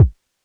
Kicks
DJP_KICK_ (157).wav